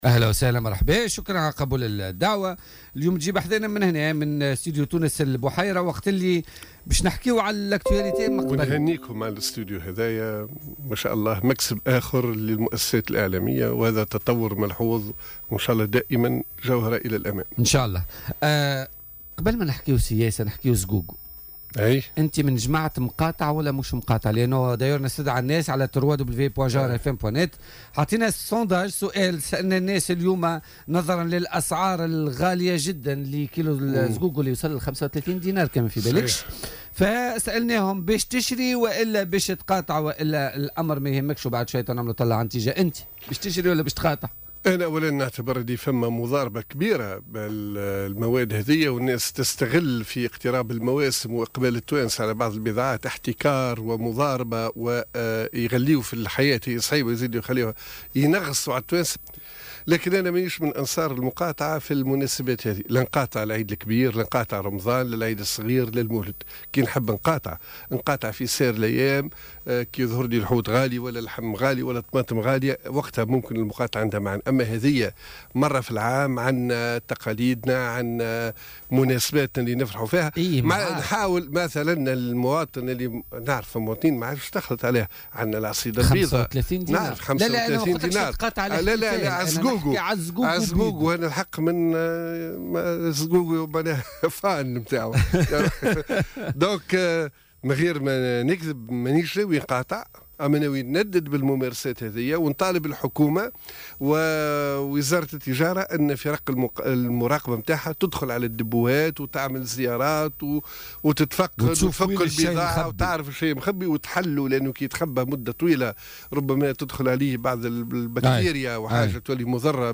وأوضح ضيف "بوليتيكا" على "الجوهرة أف أم" بوجود مضاربة في أسعار هذه المادة بالتزامن مع احتفال التونسيين بالمولد النبوي الشريف.